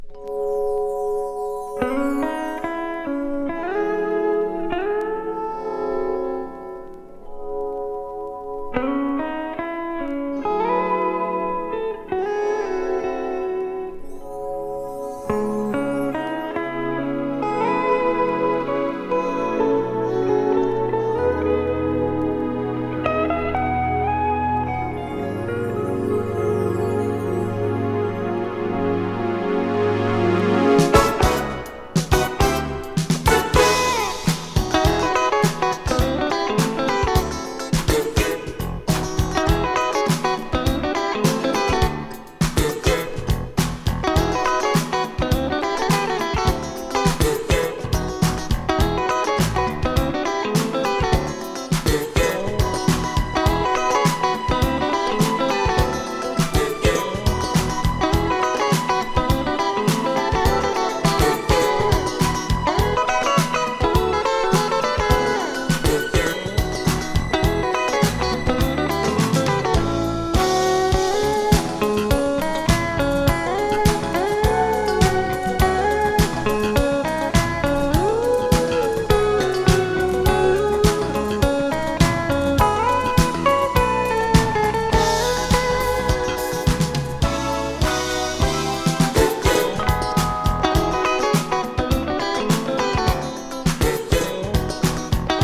爽やかな風が吹き抜けるFusionチューン。